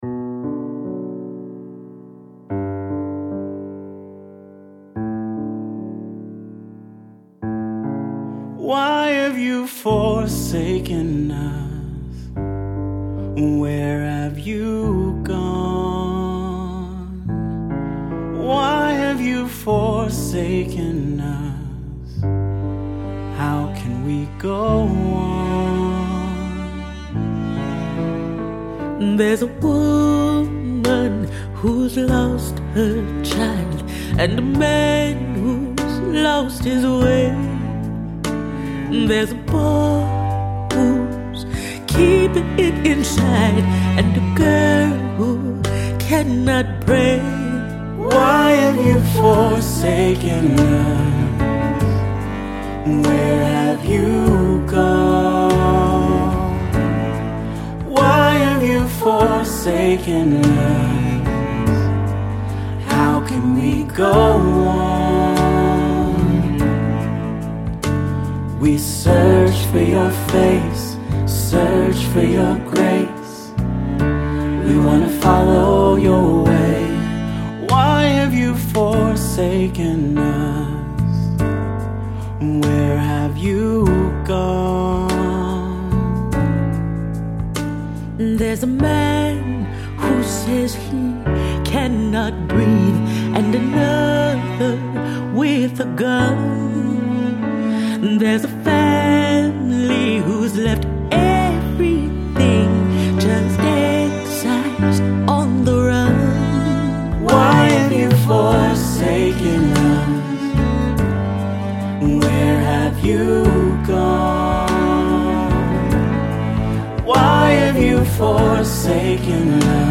Another gorgeous and soulful song of lament and longing
worship music